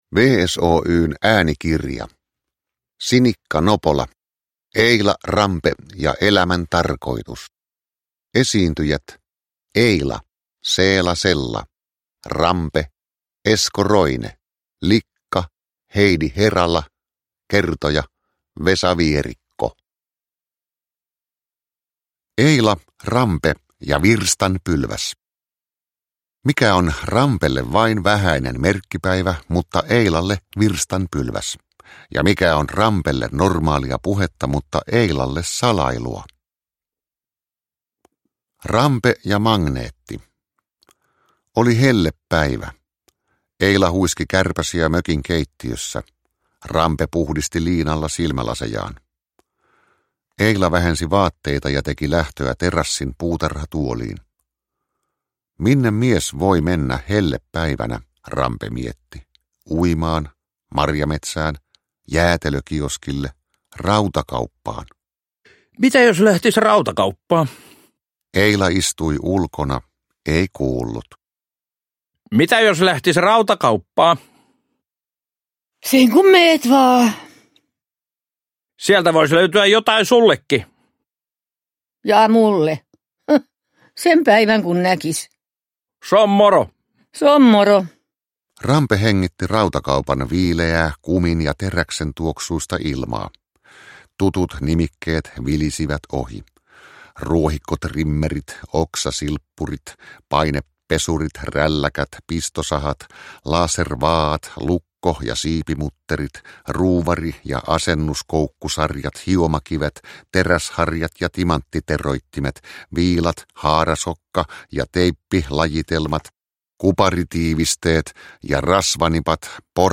Produkttyp: Digitala böcker
Uppläsare: Seela Sella, Esko Roine, Vesa Vierikko, Heidi Herala